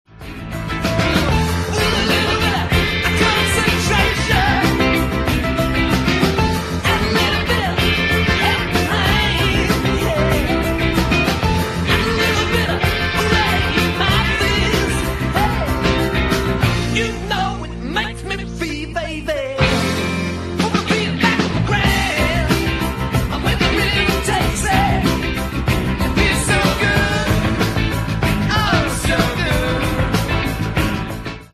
大胆なシンセサウンドをはじめ、サンバやカントリーにも挑戦した意欲作。